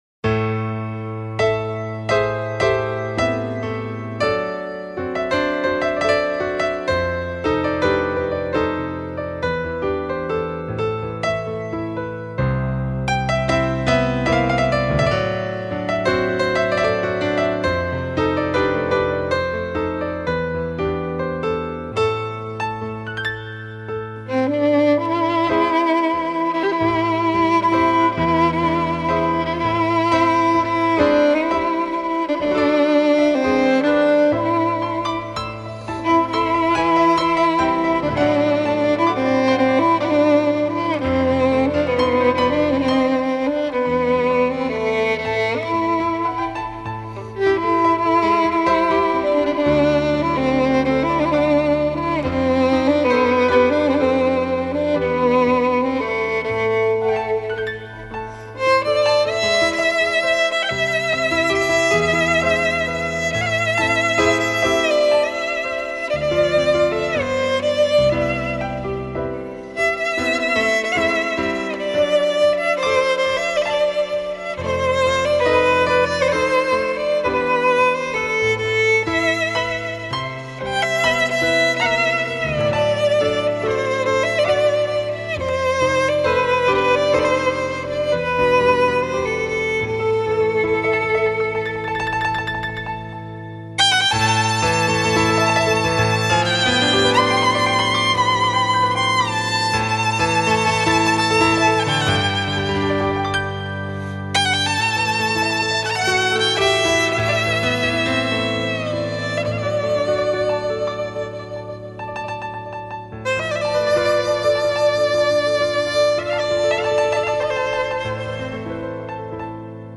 آهنگ لایت فوق العاده آرامیخش و روح نواز
ویولنیست ارمنی
[نوع آهنگ: لایت]